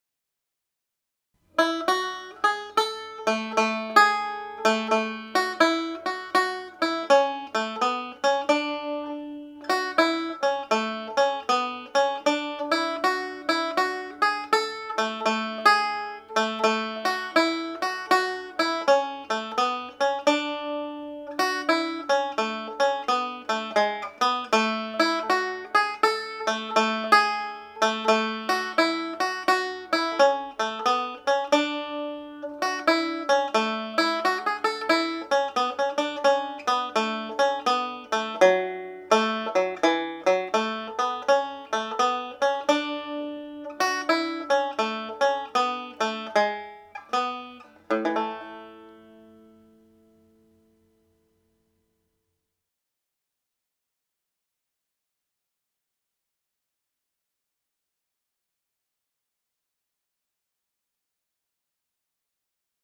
Frank’s reel has been a very popular up lifting tune in many sessions I have played in.
part two played slowly